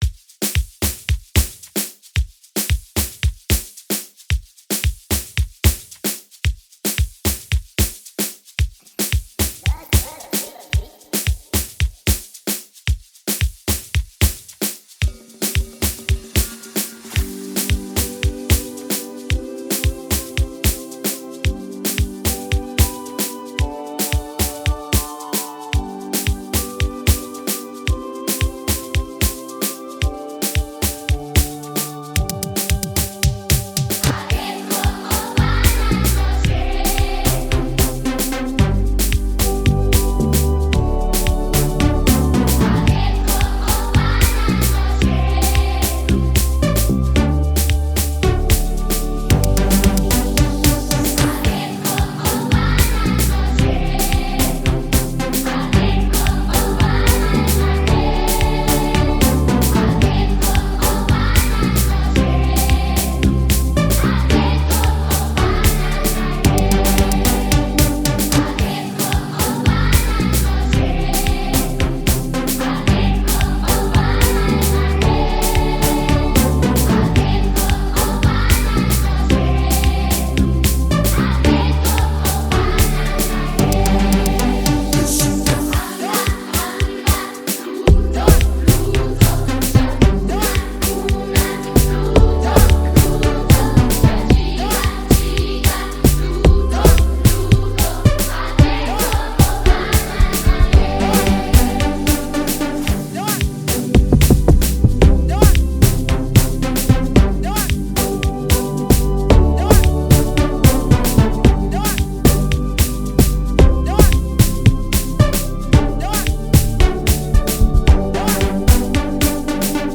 Amapiano